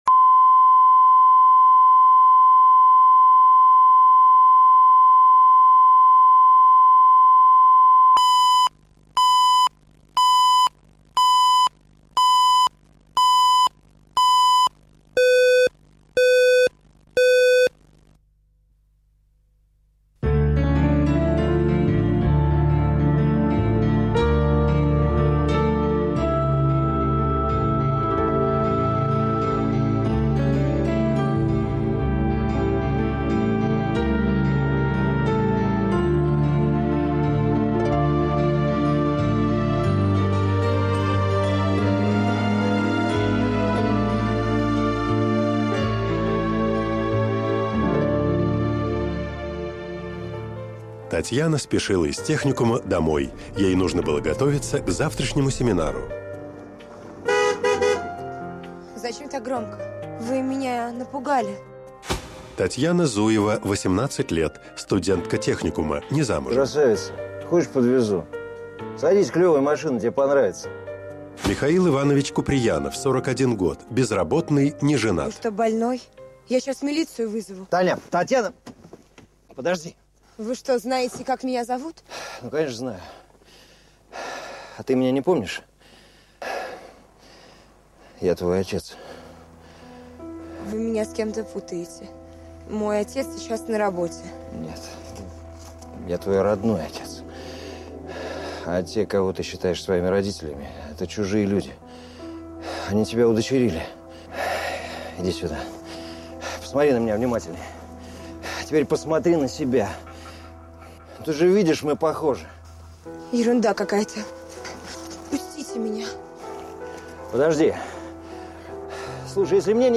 Аудиокнига Чужие | Библиотека аудиокниг